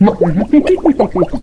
otis_start_vo_01.ogg